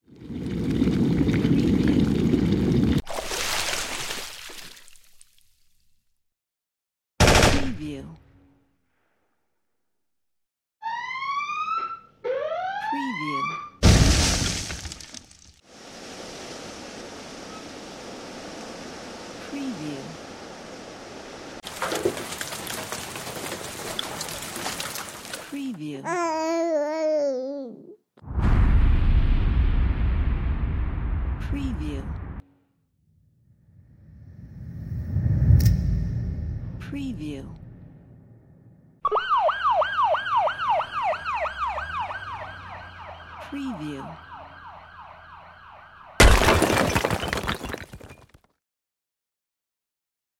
مجموعه کامل افکت صوتی | افکت صوتی | جلوه های صوتی | Sound Effect | Sound FX
به طور کلی به هر صدای غیر موسیقایی افکت صوتی گفته میشود. مثل صدای : دریا ، باران ، آب ، حیوانات ، هواپیما ، قطار ، ماشین و …
demo-sound-effect.mp3